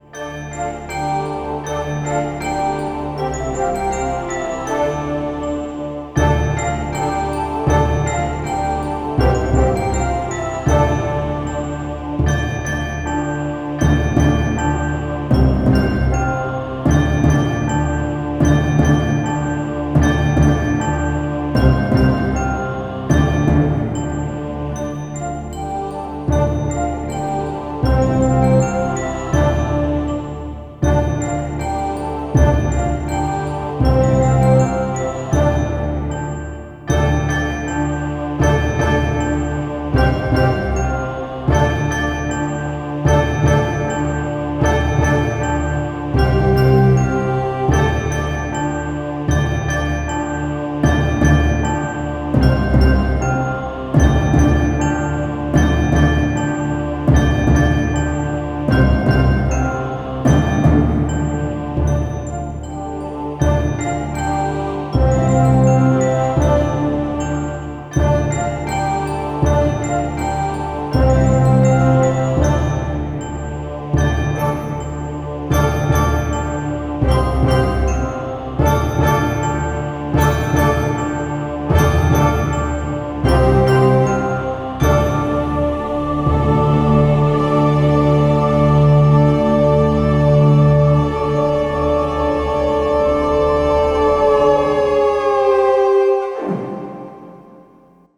Epic Soundtrack.